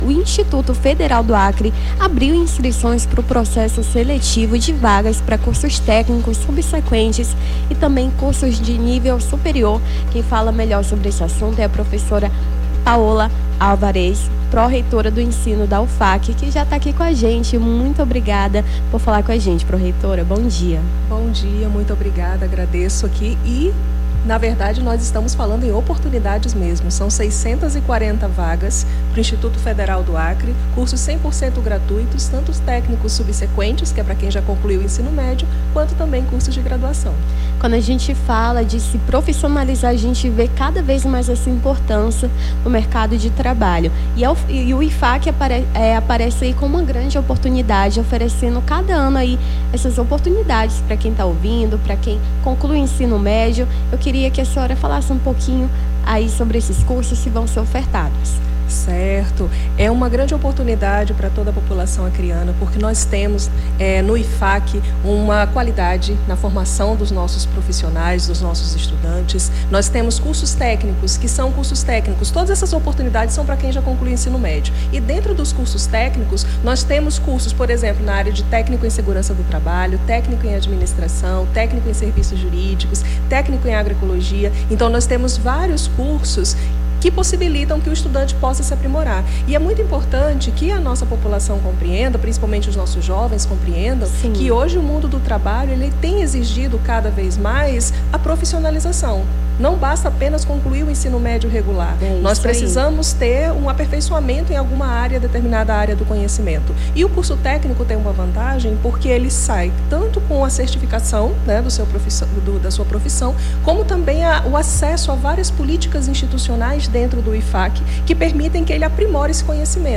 Nome do Artista - CENSURA - ENTREVISTA IFAC CURSOS TÉCNICOS (08-05-25).mp3